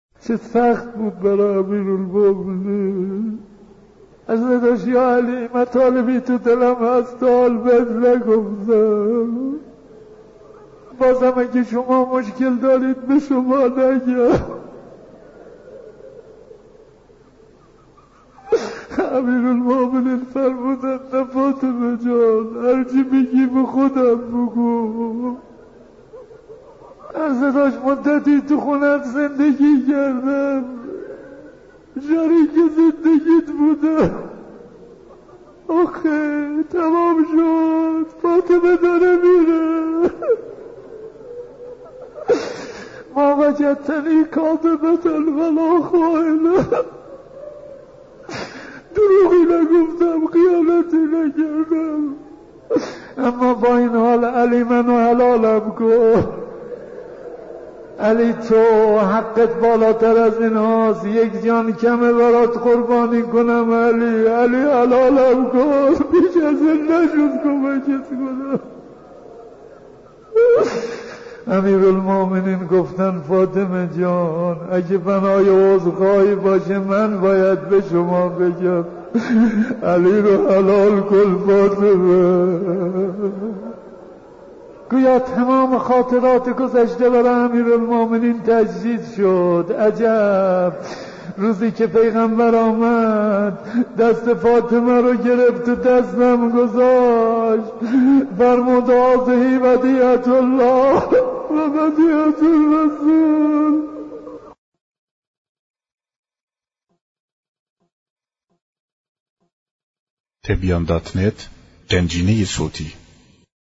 دانلود مداحی وادع با دخت نبی - دانلود ریمیکس و آهنگ جدید